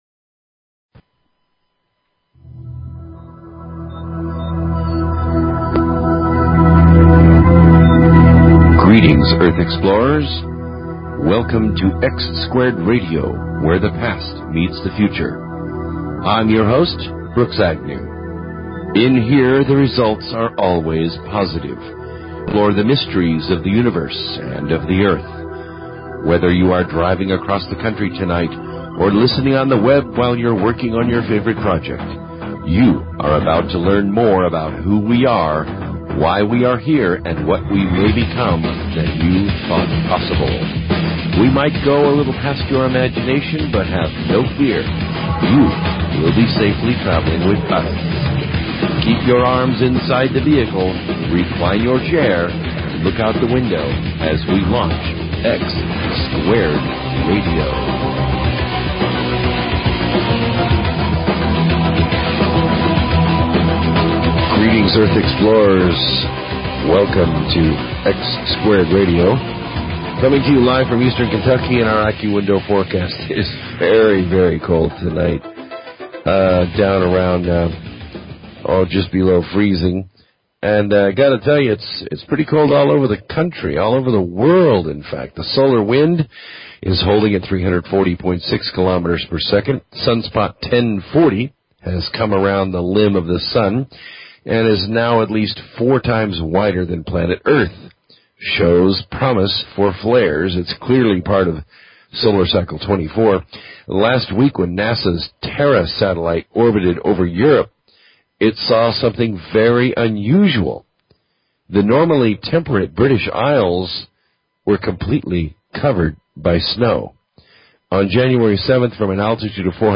Talk Show Episode, Audio Podcast, X-Squared_Radio and Courtesy of BBS Radio on , show guests , about , categorized as
Great callers as we consider the questions of the day. The program begins in hour two, as we had server problems with hour one.